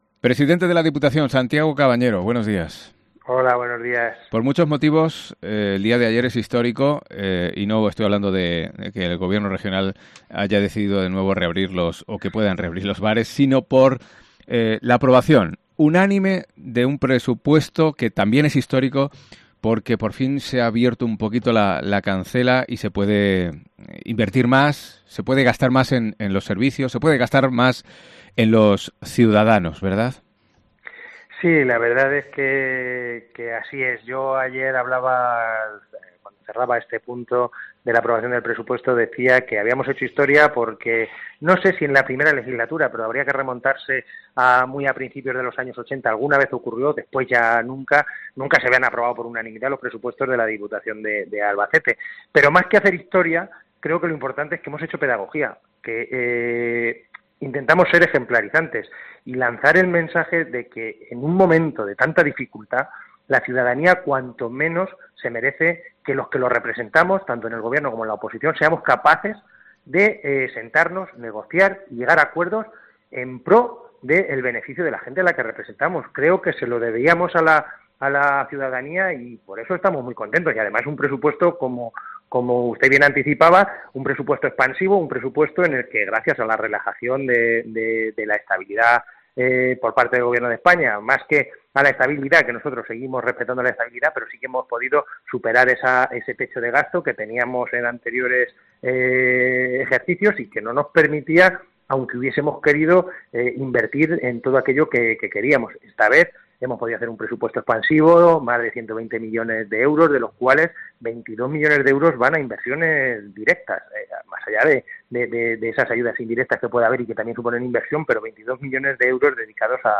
AUDIO: Entrevista con el presidente de la Diputación de Albacete tras la aprobación de las cuentas para este año en el que todos los grupos políticos...